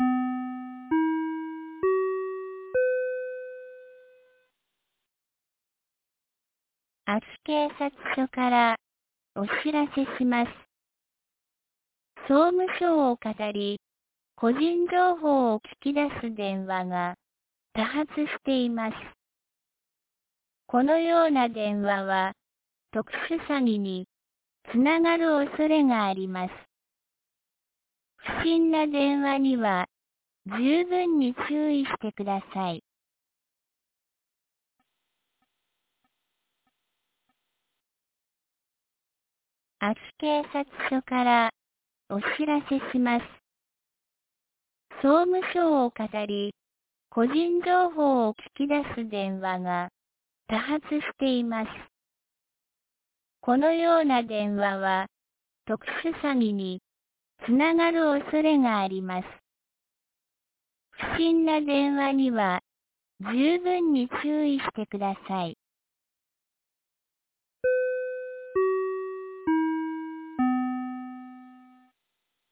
2024年08月15日 17時11分に、安芸市より全地区へ放送がありました。